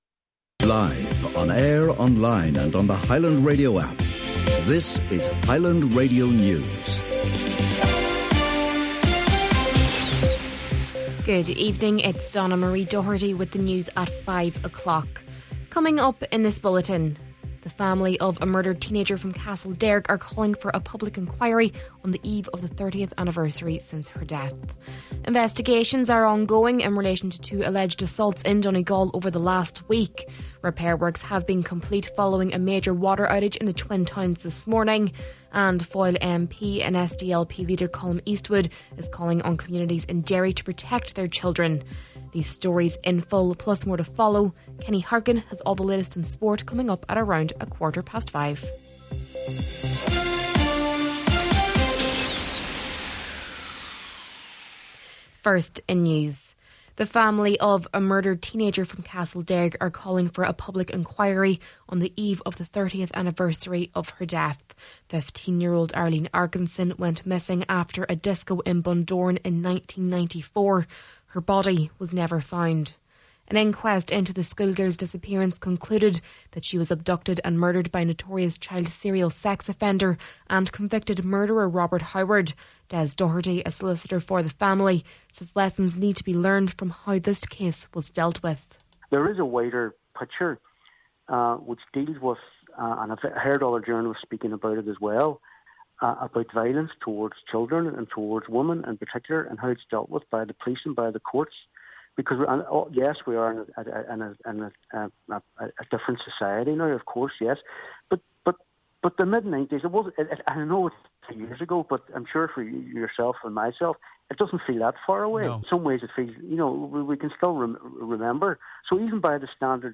Main Evening News, Sport and Obituaries – Tuesday August 13th